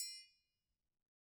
Triangle6-HitM_v1_rr1_Sum.wav